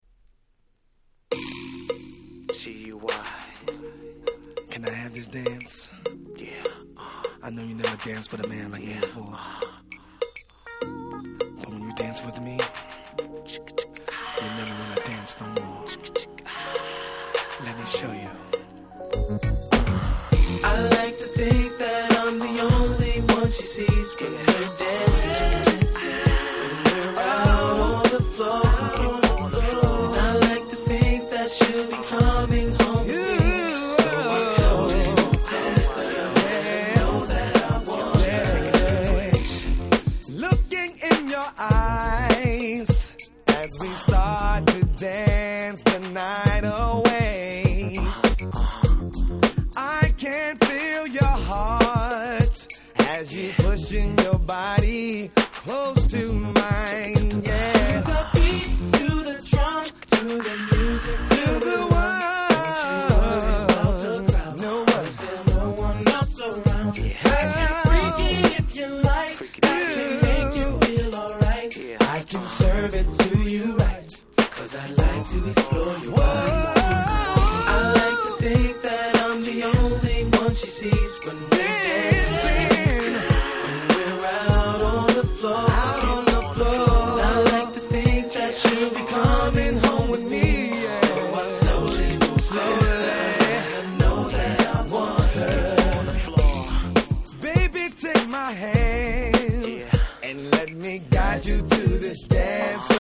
99' Super Hit R&B !!
Urbanな雰囲気で「夜」な感じの素晴らしいLate 90's R&B Classicsです。